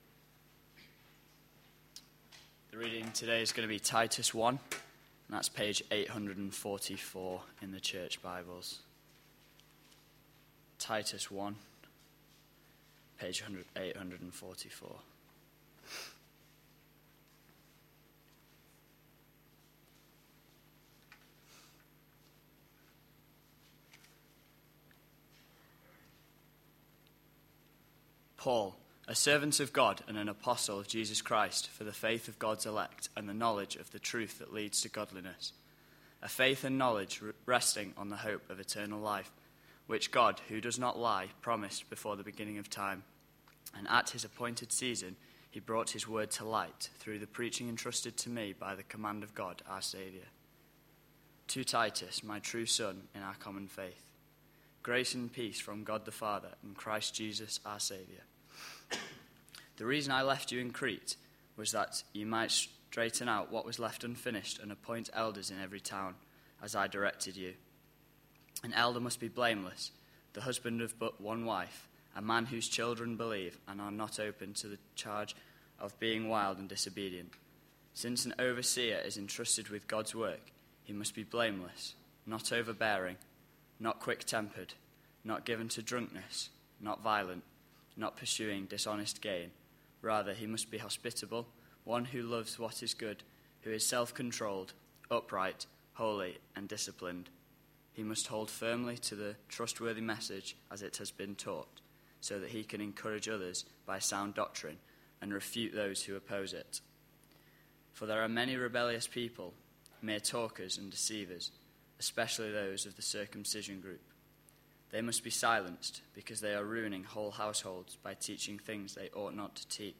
A sermon preached on 27th May, 2012, as part of our Joining In series.